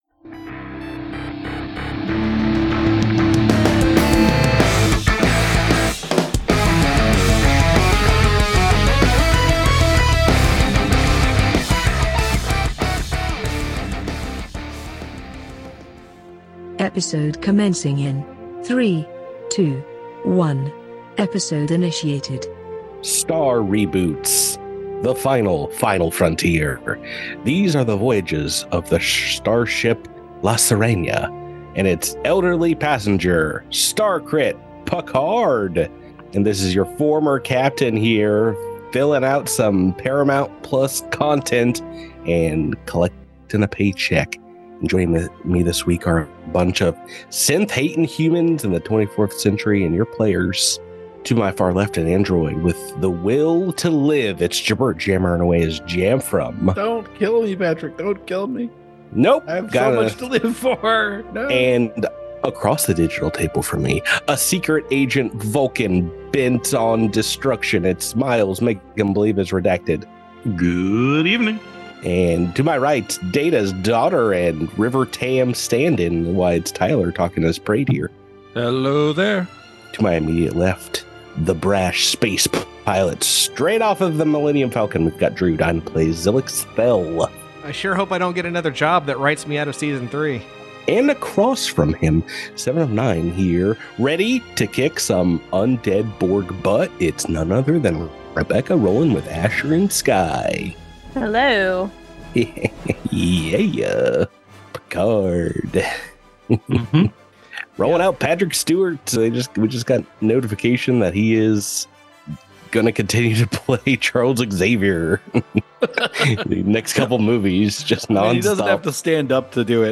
Cosmic Crit is a weekly Actual Play podcast centered on the new Starfinder RPG from Paizo. Listen to the shenanigans as a seasoned GM, a couple of noobs, and some RPG veterans explore the galaxy and fight monsters on behalf of the Starfinder Society. It's a little roleplay, a lot of natural 20s (we hope), and plenty of fun.